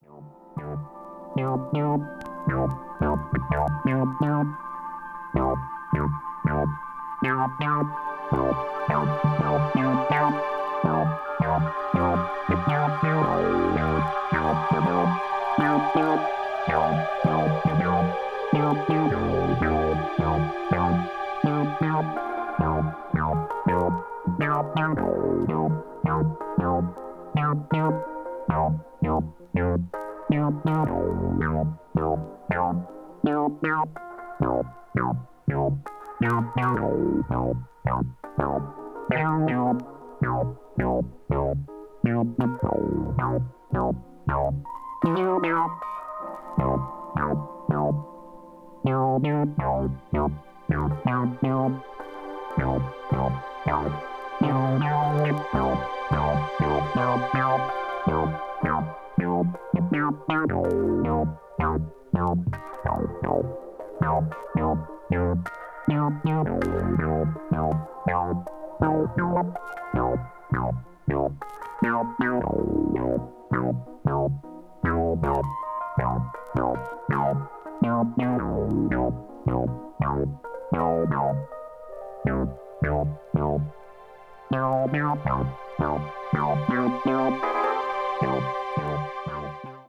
acoustic piano solo
Arp synthesizers and Fender Rhodes
spacey and exquisite electronic jazz groove